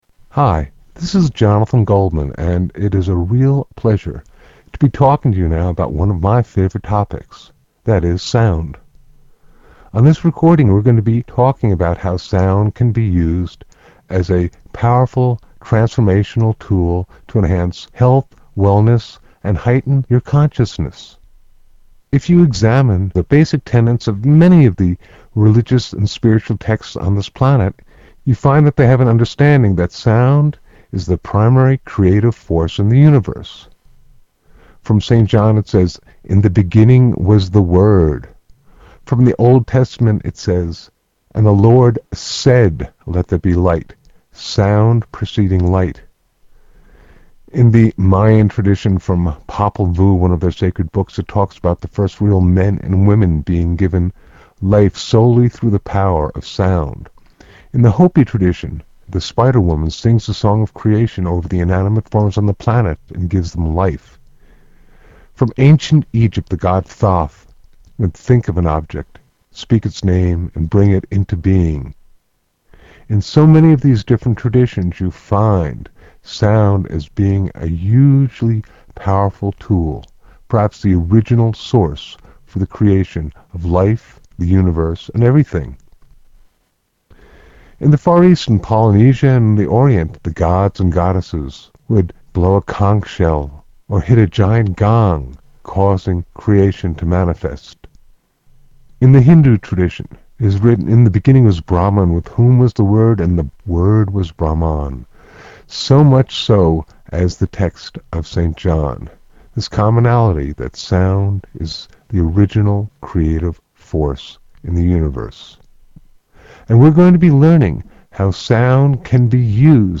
Vocal Toning the Chakras